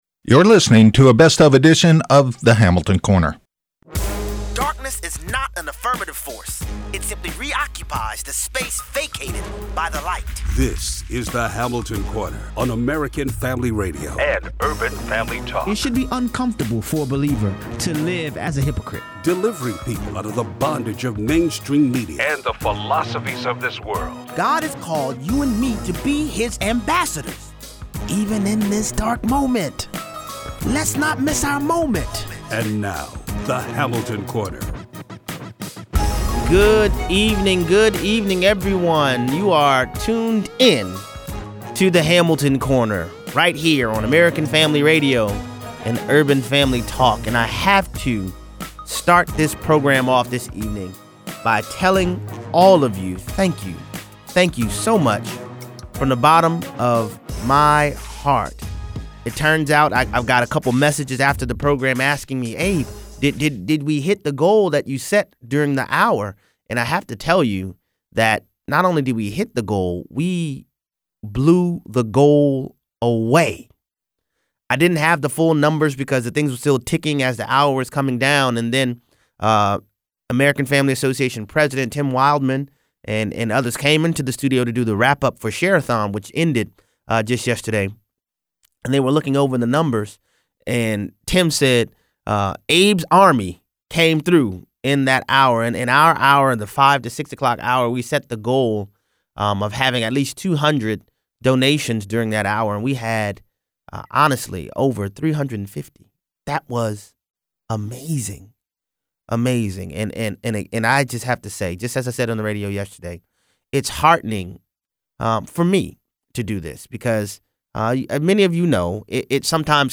A replay of the Walter Williams interview